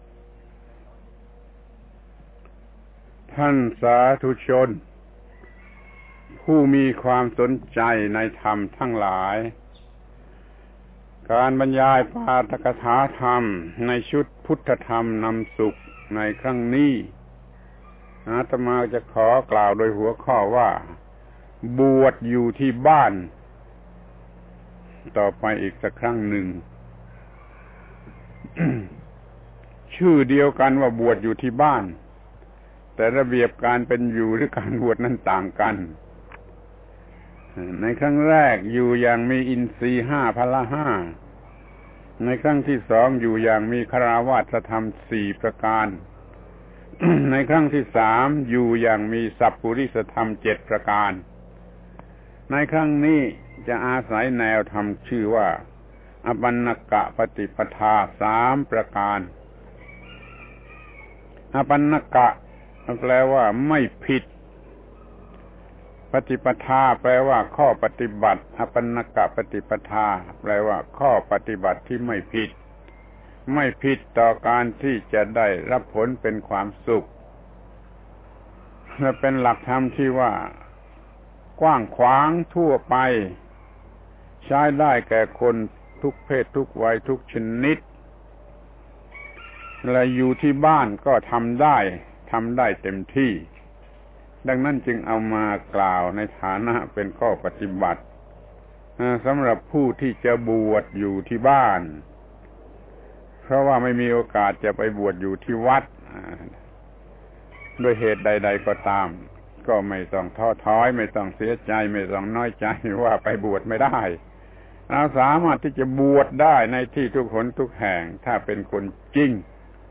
พระธรรมโกศาจารย์ (พุทธทาสภิกขุ) - ปาฐกถาธรรมทางโทรทัศน์รายการพุทธธรรมนำสุข ชุดบวชอยู่ที่บ้าน (มี ๔ ตอน) ครั้ง ๗๑ บวชอยู่ที่บ้านโดยอปัณกะปฏิปทาสามประการ